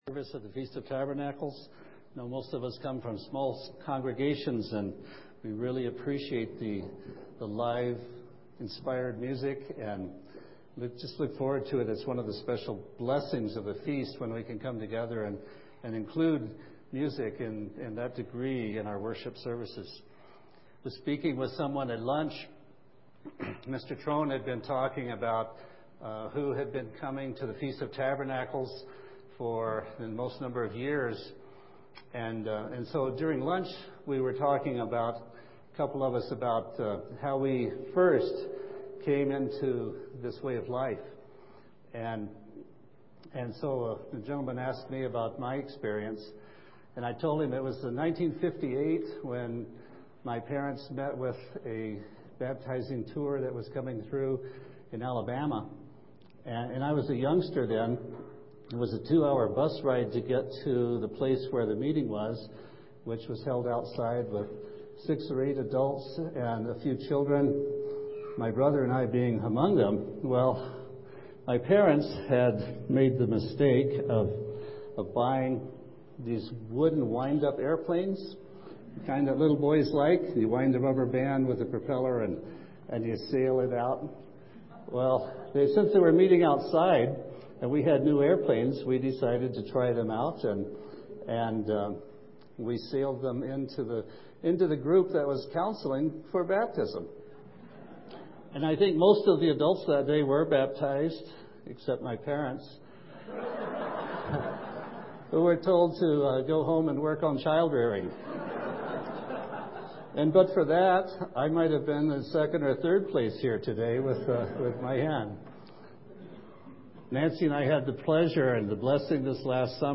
This sermon was given at the Steamboat Springs, Colorado 2011 Feast site.